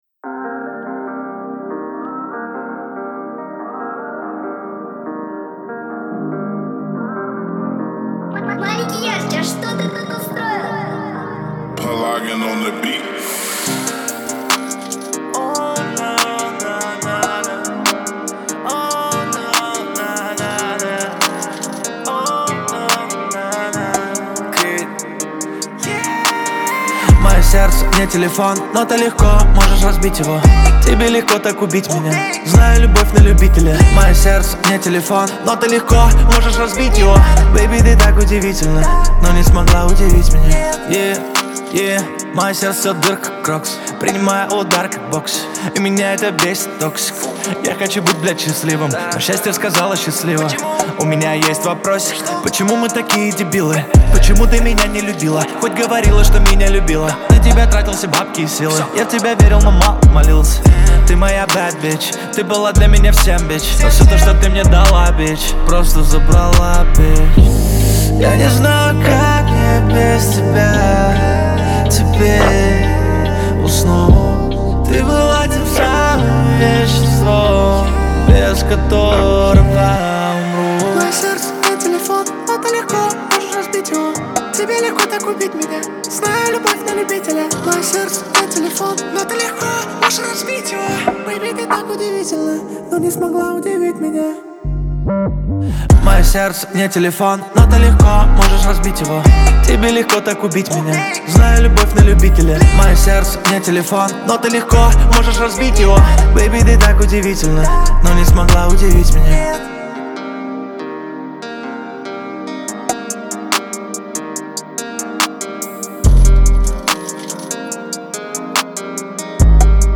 выполненная в жанре поп и R&B. Это трек о сложных отношениях